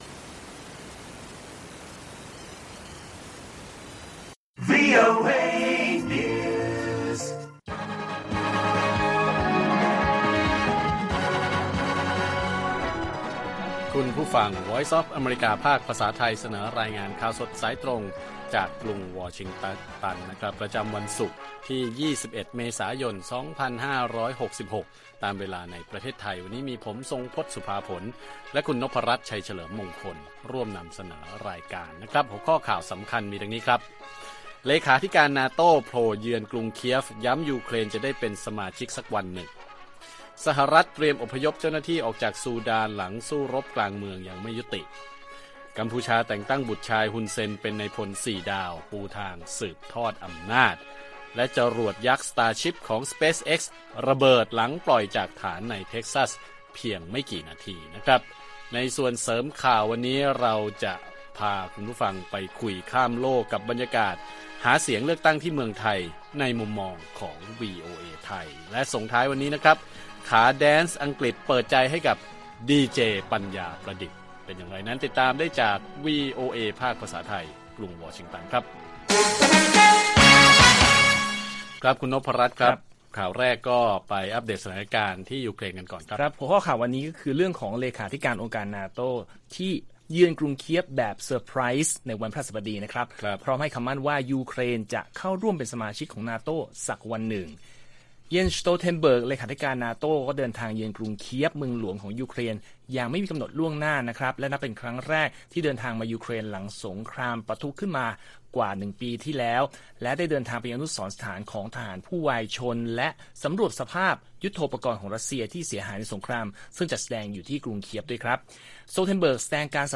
ข่าวสดสายตรงจากวีโอเอไทย 6:30 – 7:00 น. วันที่ 21 เม.ย. 2566